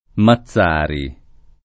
[ ma zz# ri o ma ZZ# ri ]